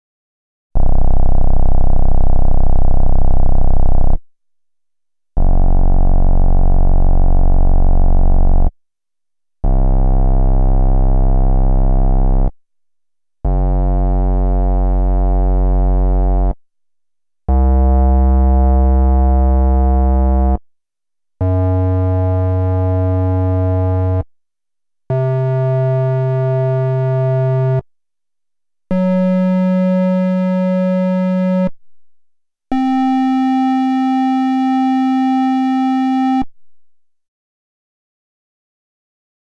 02_Bass_3.wav